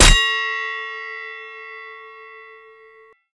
bell_impact_EnlSPNh.mp3